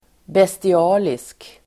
Ladda ner uttalet
bestialisk adjektiv, bestial Uttal: [besti'a:lisk] Böjningar: bestialiskt, bestialiska Synonymer: grym, rå Definition: grym och omänsklig (cruel and inhuman) Exempel: ett bestialiskt mord (a brutal murder)